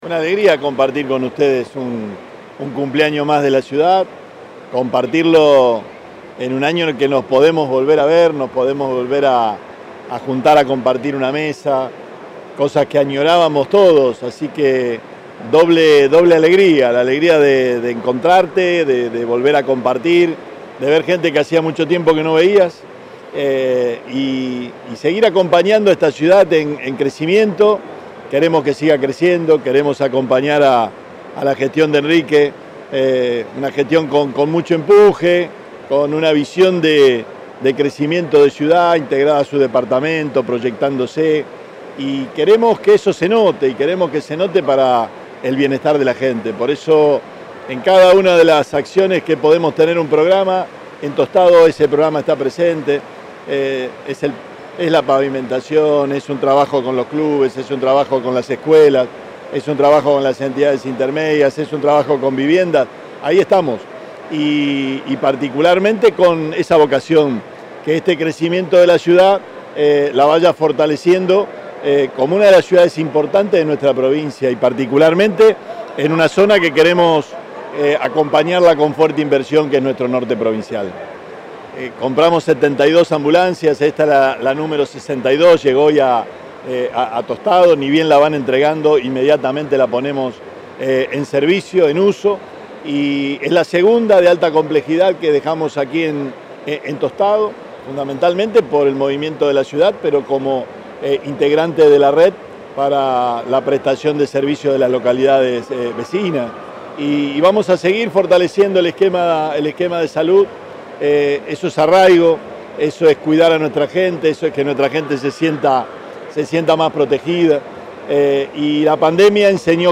El gobernador Perotti, junto con el intendente Mualem, durante la celebración de los 130 años de la ciudad de Tostado.
Declaraciones del gobernador Perotti y del intendente Mualem